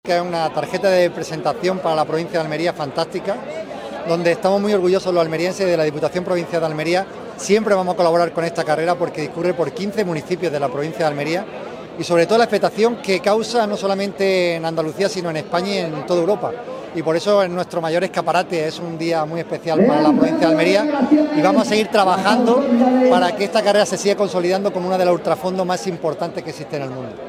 JAVIER-AURELIANO-GARCIA-PRESIDENTE-DIPUTACION-SALIDA-DESERTICA.mp3